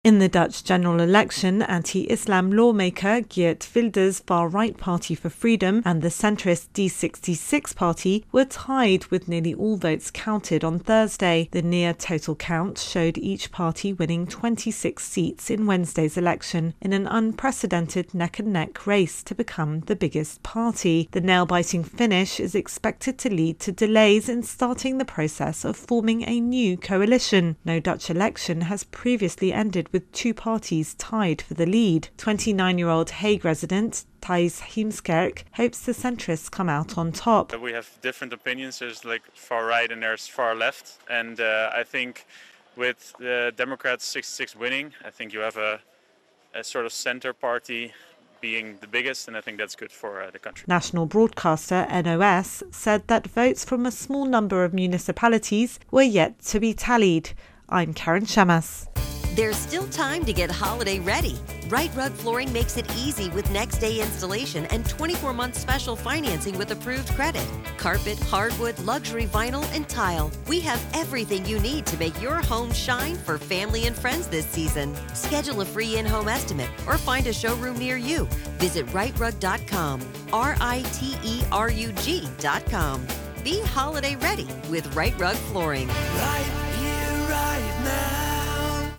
AP correspondent reports on the latest in the Netherlands elections results.